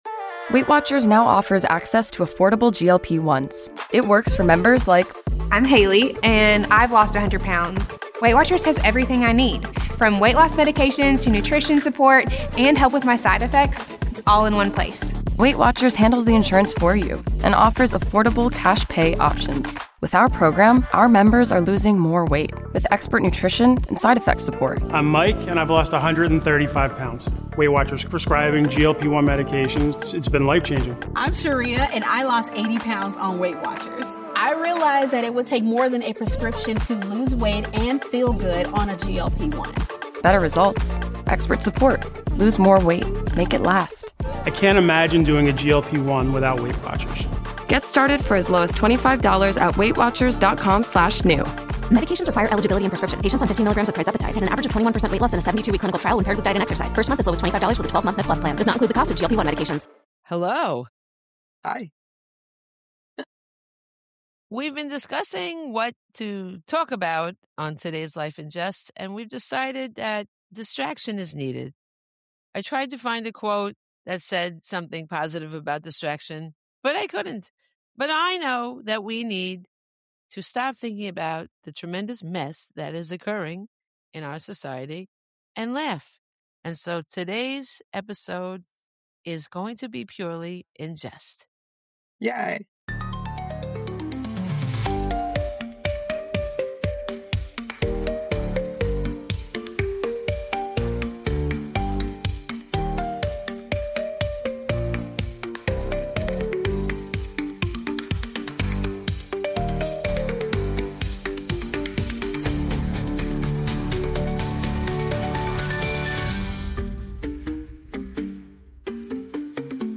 What follows is a rapid-fire exchange of surprising facts, strange history, pop culture deep cuts, and genuinely mind-bending discoveries.